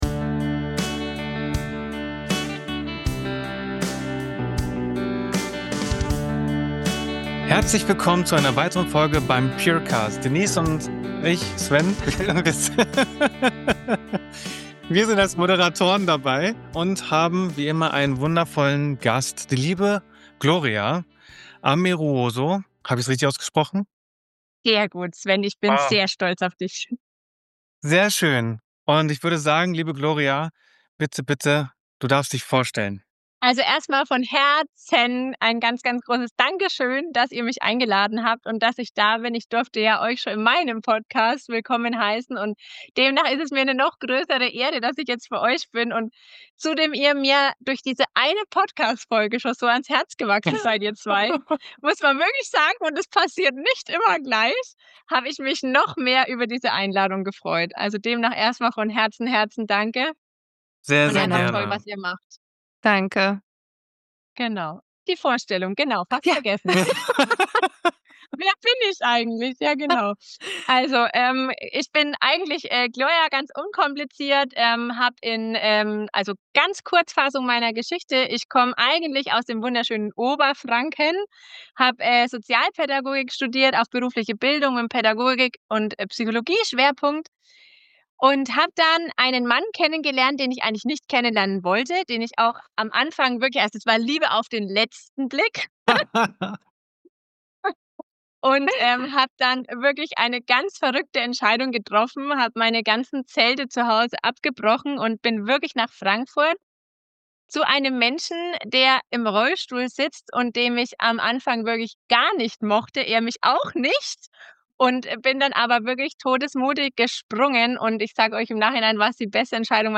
Dabei spricht sie offen über Herausforderungen in der Zusammenarbeit, ihre Mission, Solidarität unter Frauen zu stärken, und die wertvollen Führungslektionen, die sie aus der Natur ableitet. Ein Gespräch voller Inspiration, ehrlicher Einblicke und praktischer Tipps, um sowohl im Business als auch privat authentisch zu führen und zu leben.